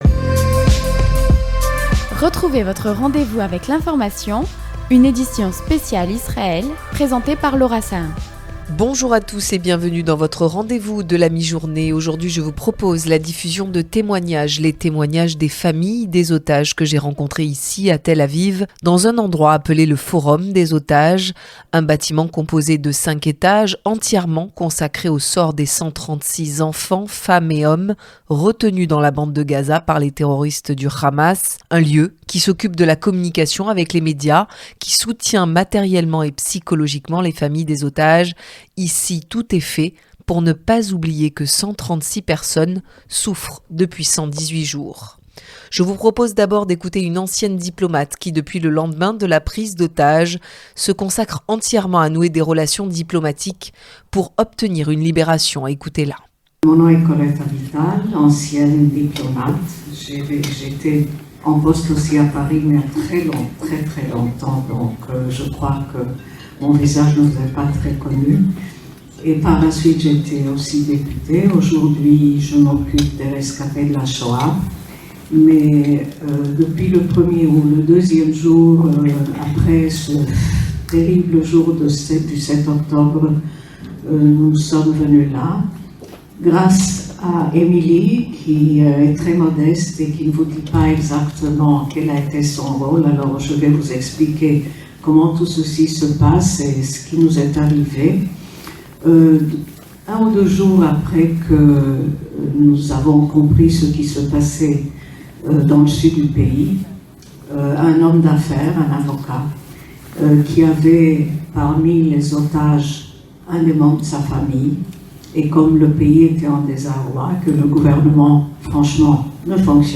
les témoignages de familles d’otages rencontrées à Tel Aviv dans un lieu dédié au 136 personnes retenues depuis 118 jours, Le forum des otages.